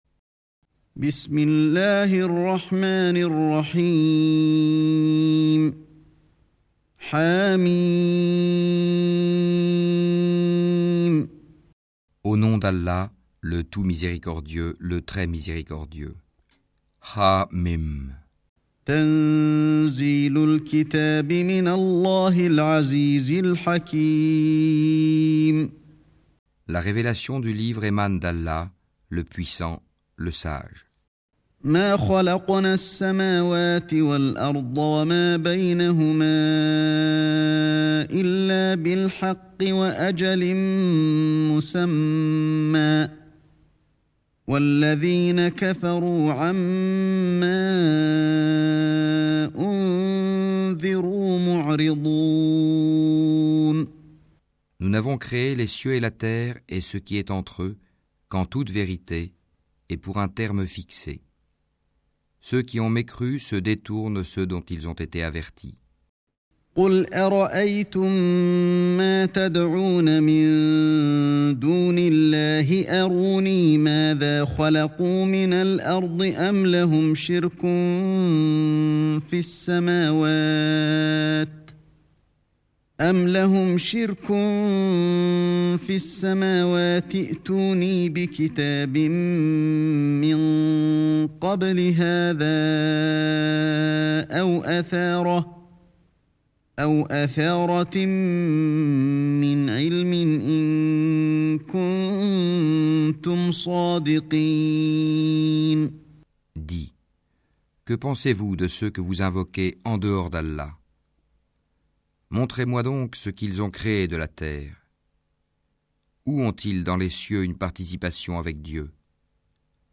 R�citation De Le Saint Coran avec Fran�aise Traduction